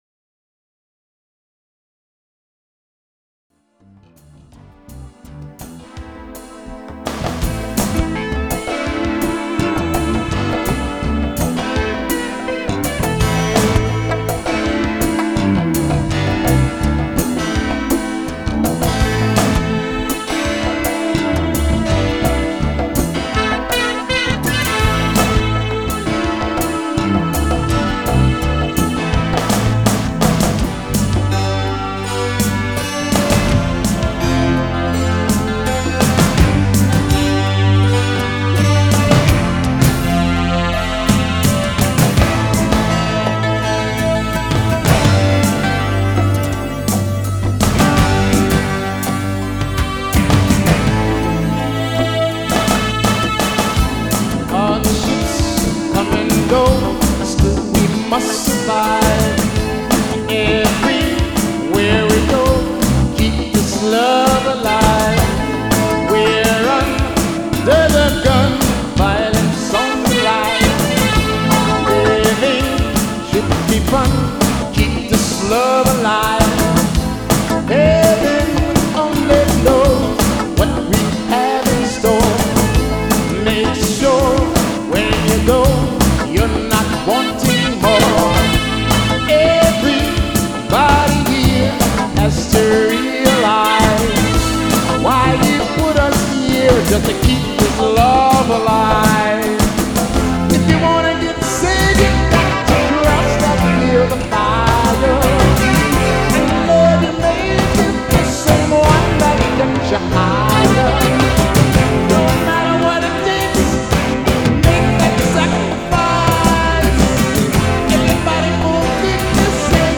live music from the crystal dome, check it out…